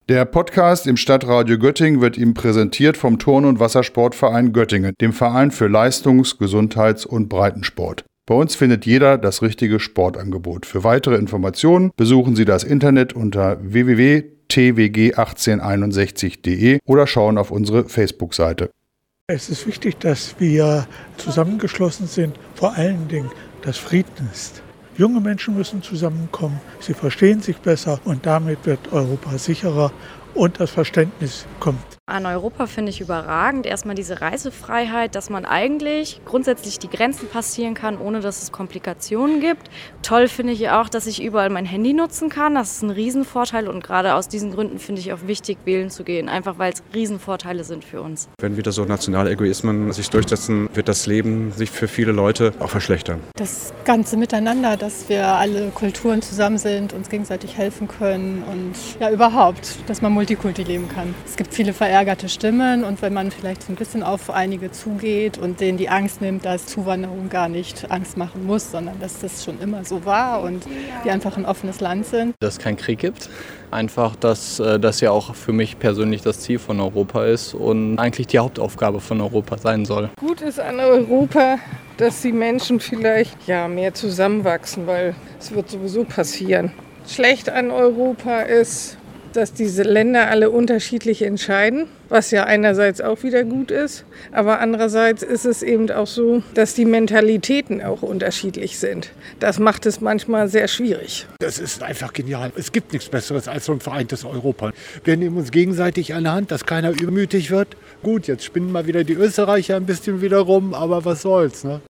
war in der Innenstadt und hat sich umgehört, was die Göttingerinnen und Göttinger denn gut an Europa finden und welche Hürden ihrer Meinung nach möglicherweise noch zu bewältigen sind.